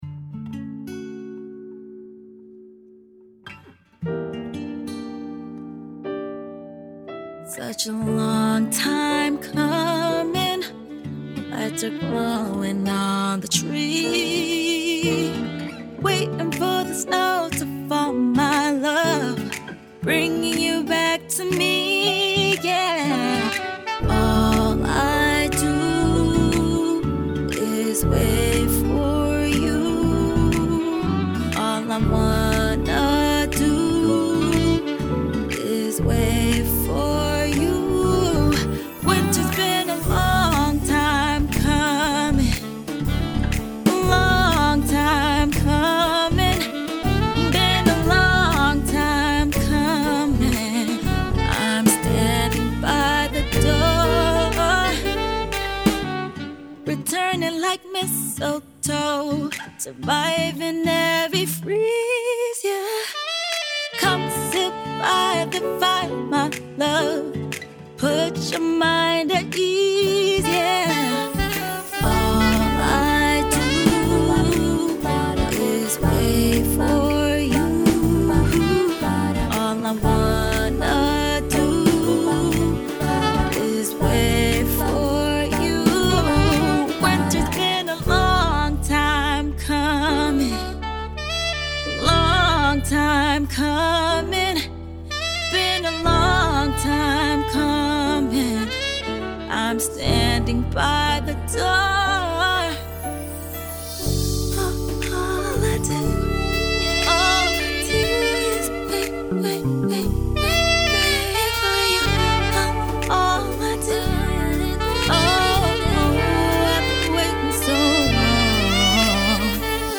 Genre: Holiday, pop, singer/songwriter
Tags: Bright, Uptempo, Romantic, female vocalist